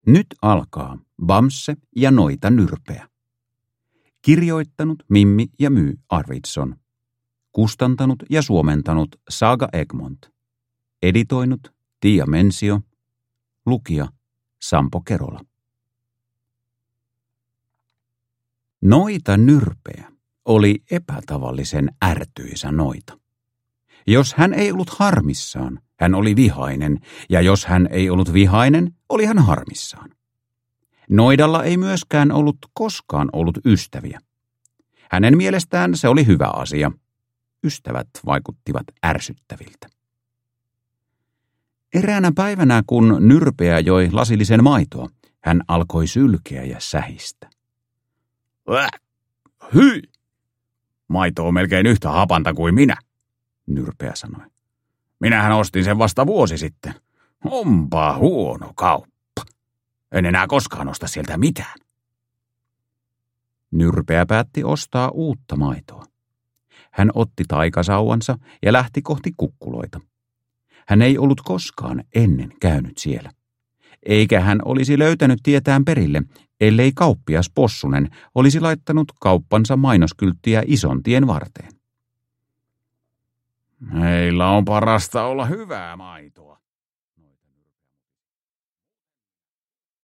Bamse ja Noita Nyrpeä – Ljudbok